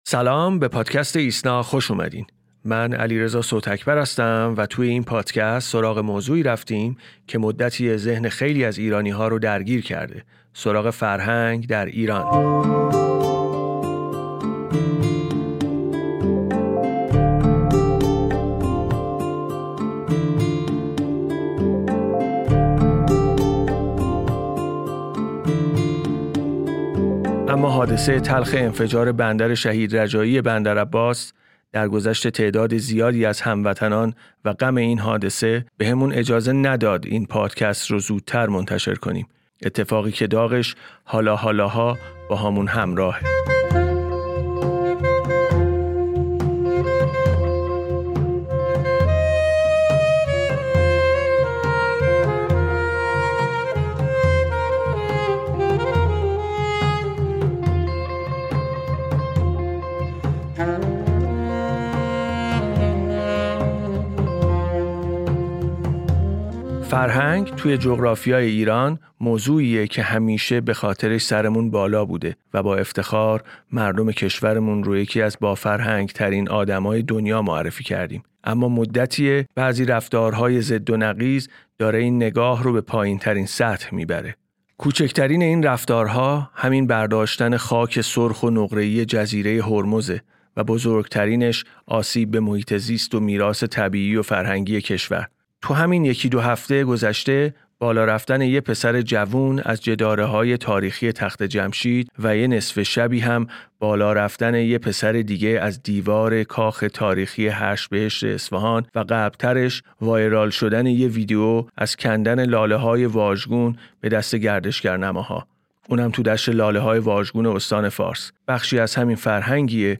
نوروز امسال مسئله برداشت خاک‌ سرخ و ماسه‌های نقره‌ای جزیره هرمز از سوی گردشگران نوروزی به یک بحث جنجالی در شبکه‌های اجتماعی تبدیل شد. در این پادکست ایسنا به سراغ این موضوع رفته و با گفت و گو با مسئولان جزیره و کارشناسان محیط زیست علت این ناهنجاری را بررسی کرده است.